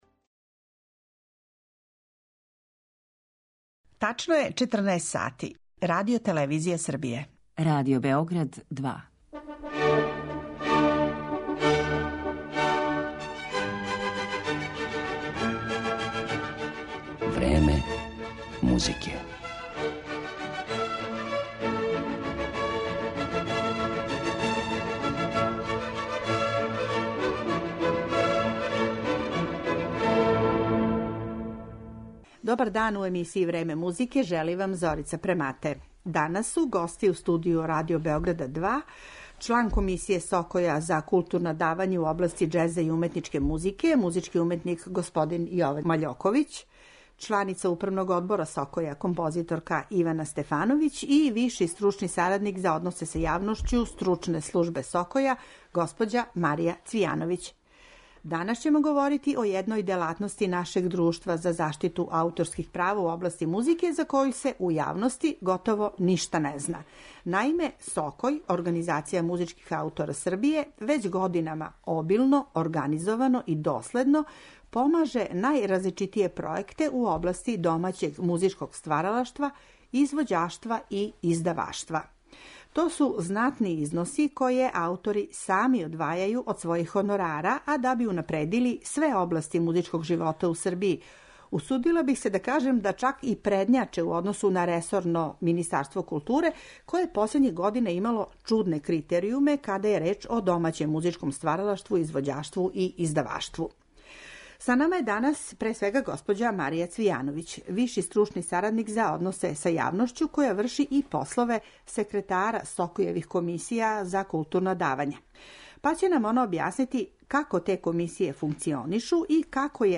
Чућемо како је организован рад ове комисије, колико је пројеката подржано и који су најважнији од њих, а у емисији ћемо слушати музичка дела чије је извођење и снимање остварено баш захваљујући овој СОКОЈ-евој делатности.